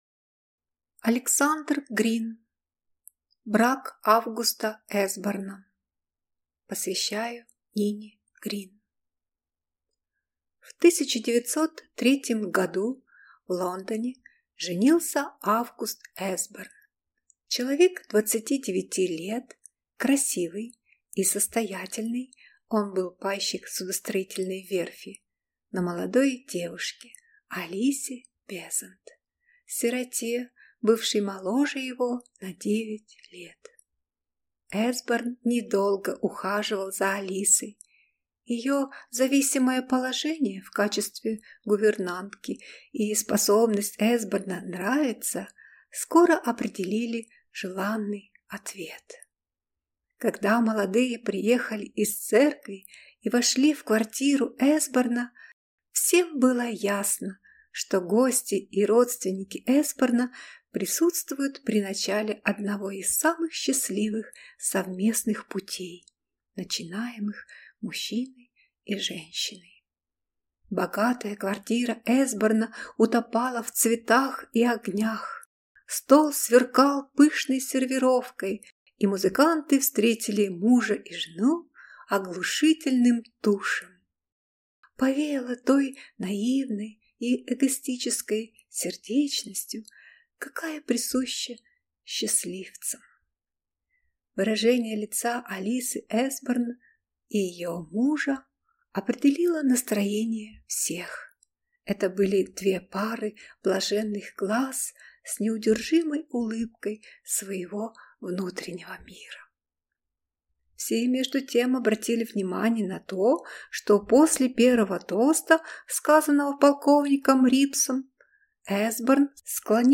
Аудиокнига Брак Августа Эсборна | Библиотека аудиокниг